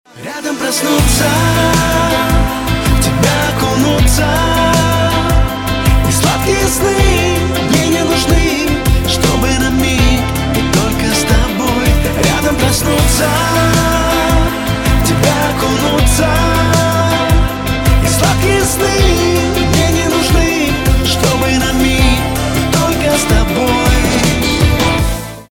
• Качество: 256, Stereo
поп
баллада